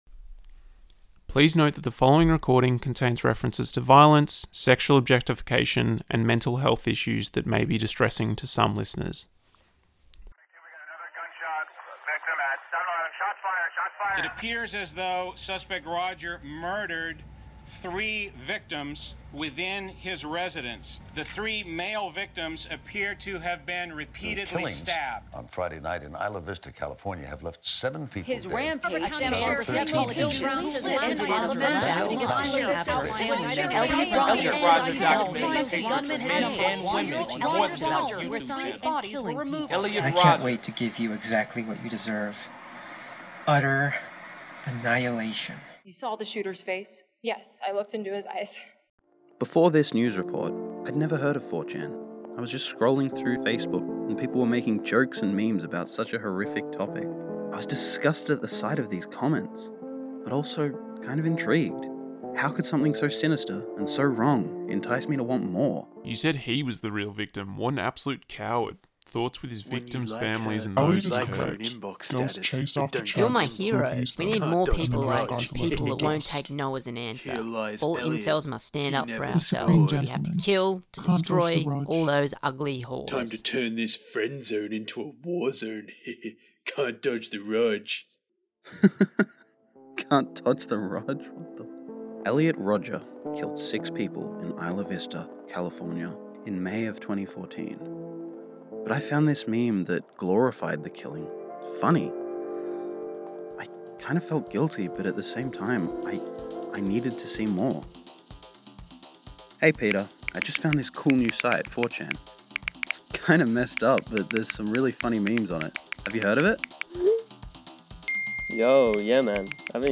A short, informative audio drama about the online incel community.
I edited this piece and provided some voicework, as well as helping to research and write it.